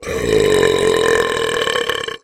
Звуки отрыжки
Отрыжка громкая как рык льва